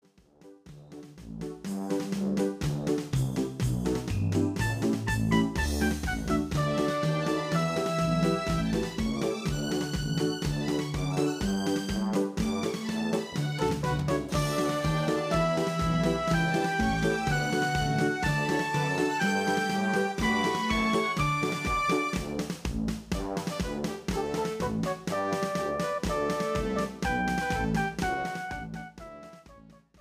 (KARAOKE)  (Folk)